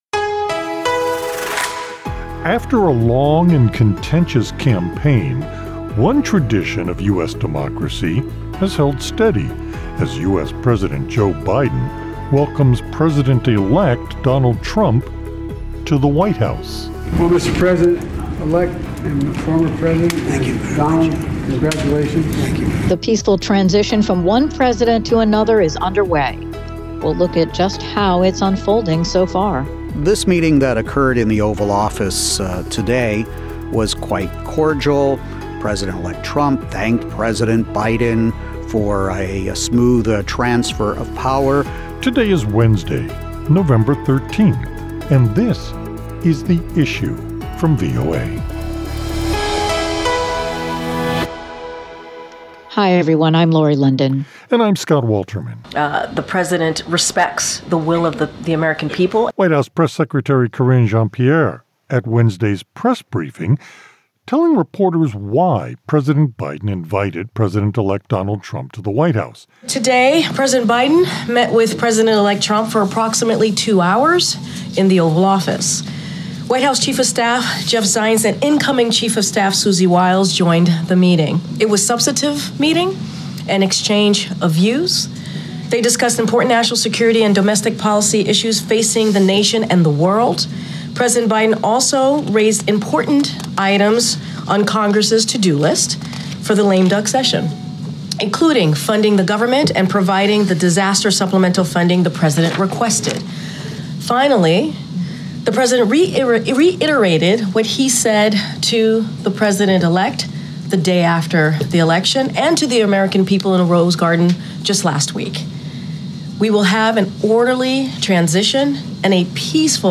A conversation about that tradition